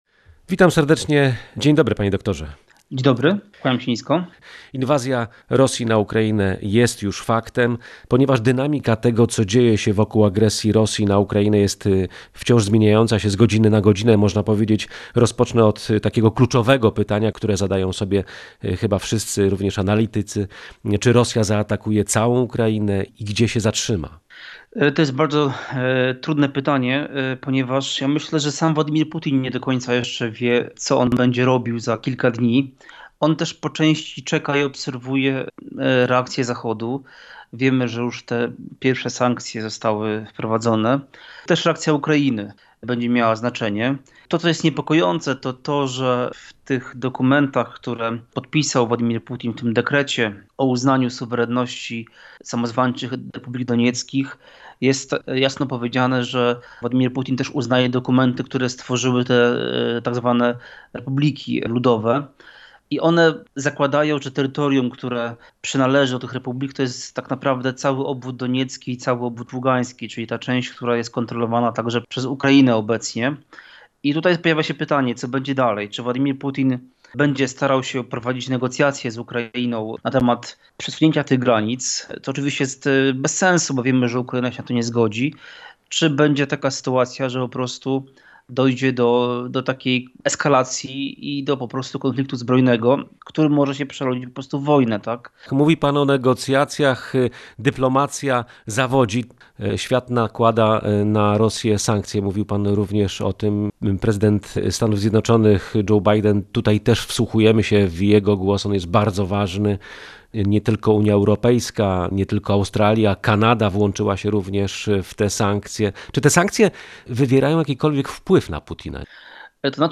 historyk i politolog